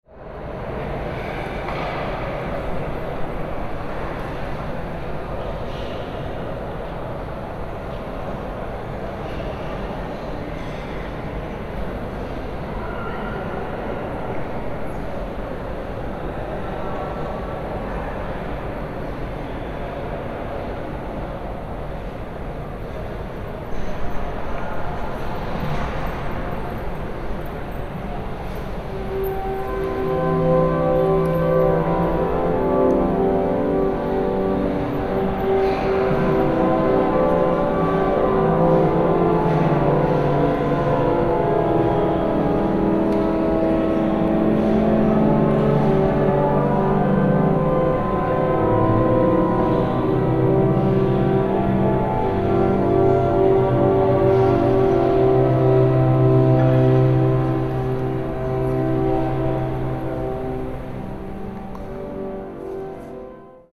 Large Church Interior Ambience Echoing Sound Effect
Description: Large church interior ambience echoing sound effect. The sound of church organs can be heard in the background as musicians rehearse for an upcoming concert.
Genres: Sound Effects
Large-church-Interior-ambience-echoing-sound-effect.mp3